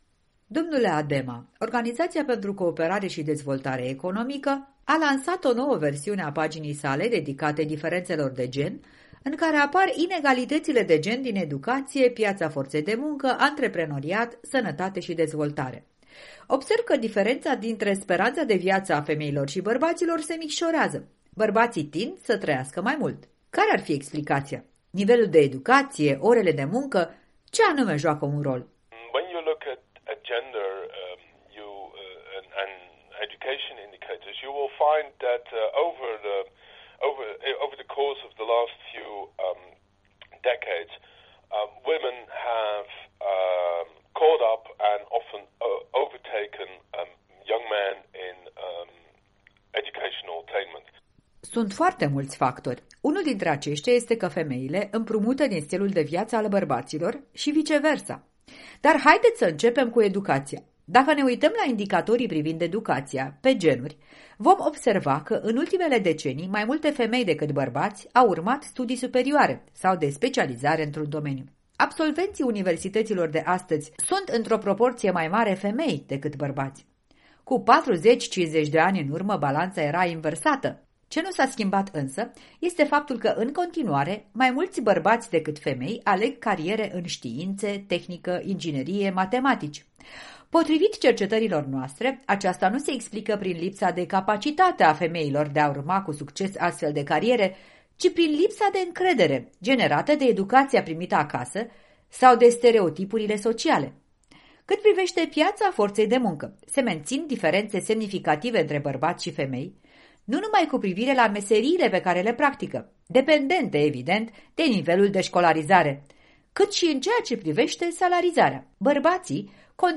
Un interviu cu expertul în economie de la Organizația pentru Cooperare și Dezvoltare Economică (OCDE).